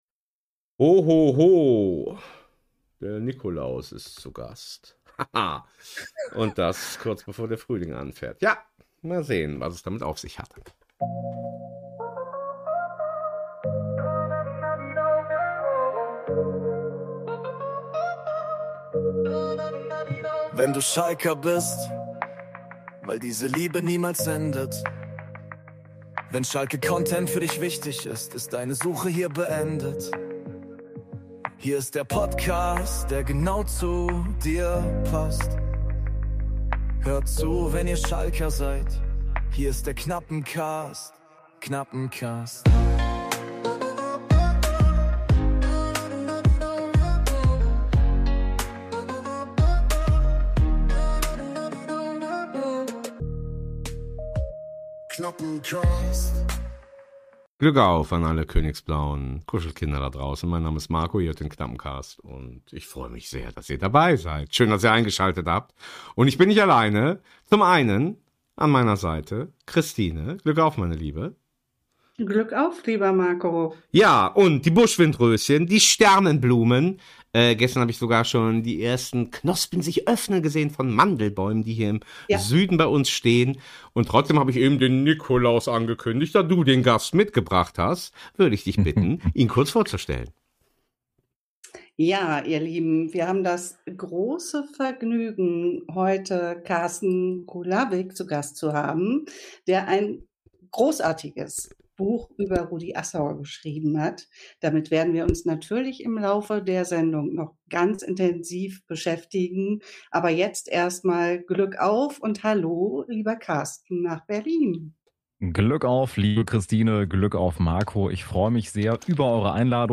In diesem wunderbaren Gespräch geht es nicht nur um Ergebnisse, sondern um Strukturen, Verantwortung und die Frage, wohin der Weg führen kann. Gleichzeitig dürfen wir auch dem Menschen näherkommen, seine Sicht auf Verein, Umfeld und persönliche Haltung kennenlernen und erfahren, was ihn im Kontext Schalke bewegt.